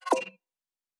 pgs/Assets/Audio/Sci-Fi Sounds/Interface/Digital Click 14.wav at master
Digital Click 14.wav